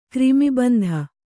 ♪ krimi bandha